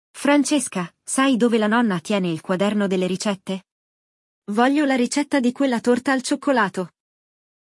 Il dialogo